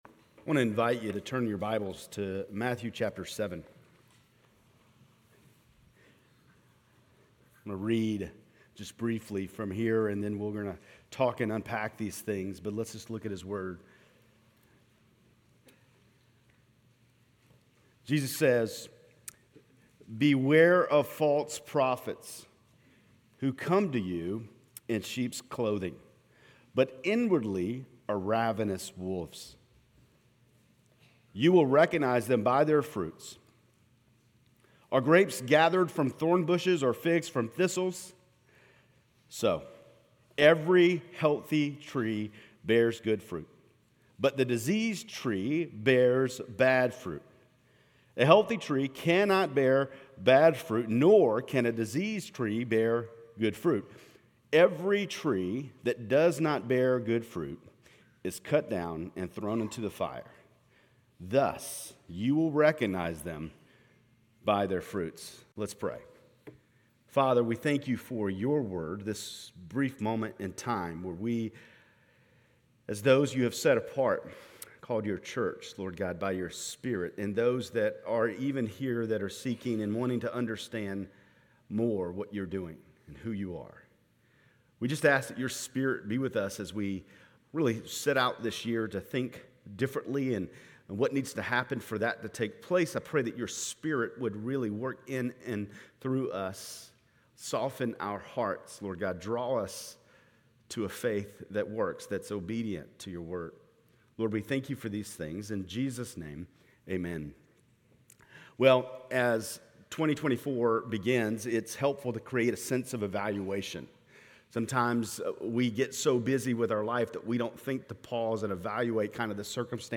Grace Community Church Lindale Campus Sermons Missional Alignment on Relational Evangelism Jan 08 2024 | 00:24:38 1x 00:00 / 00:24:38 Subscribe Share RSS Feed Share Link Embed